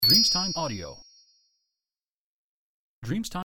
Carillon magico 0002 di scintillio
• SFX